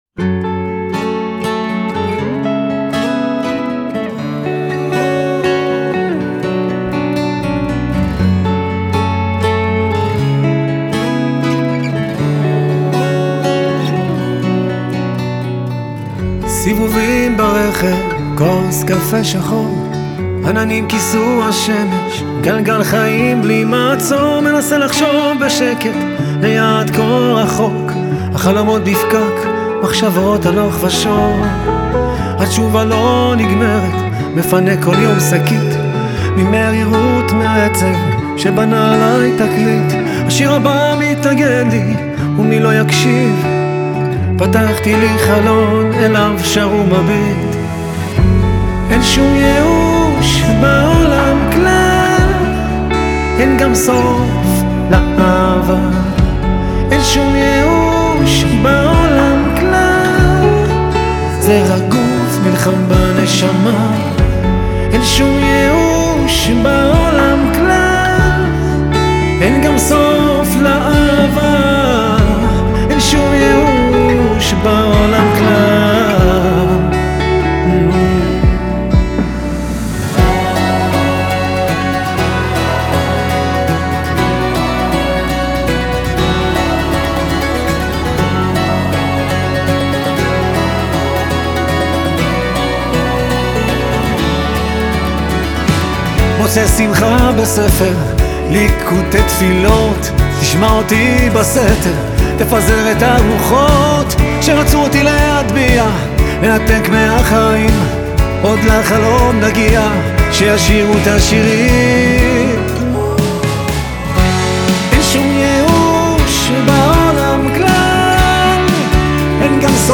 גיטרות ובזוקי
באס